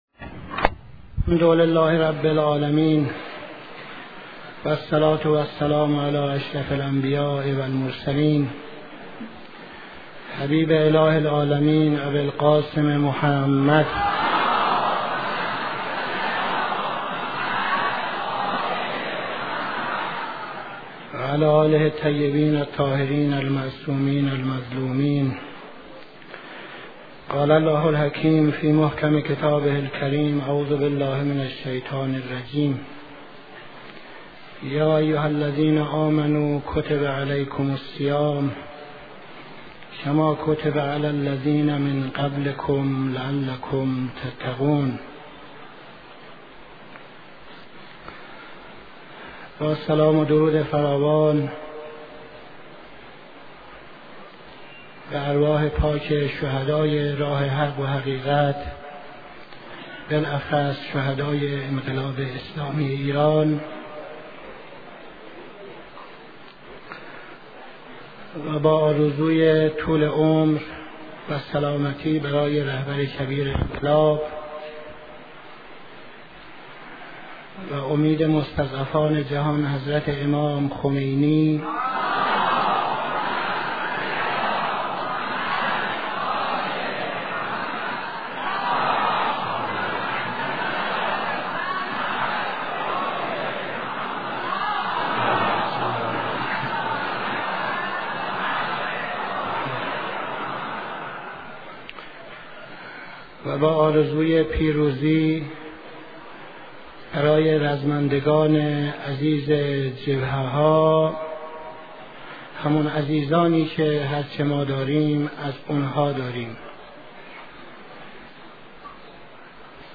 اخلاق و خودسازی (قبل از خطبه‌های نماز جمعه تهران)